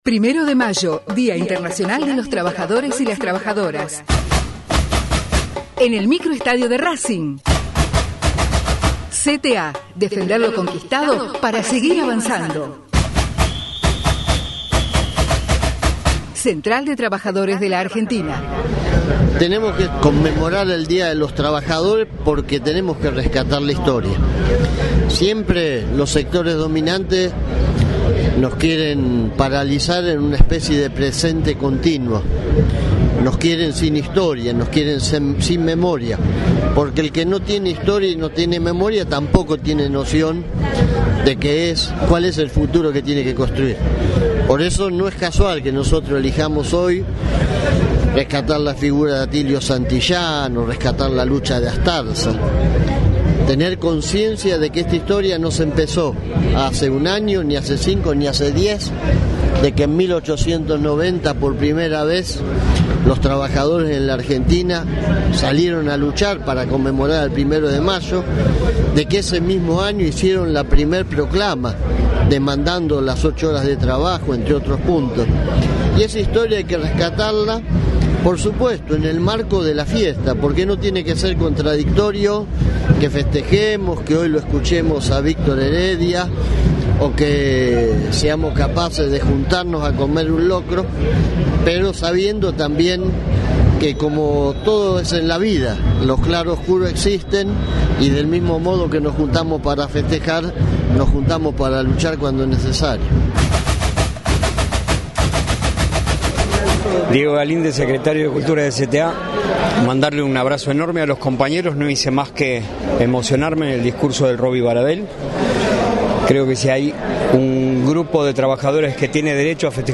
TESTIMONIOS - ACTO 1º DE MAYO - RACING CLUB, AVELLANEDA
La palabra de los compañeros dirigentes en el acto de la CTA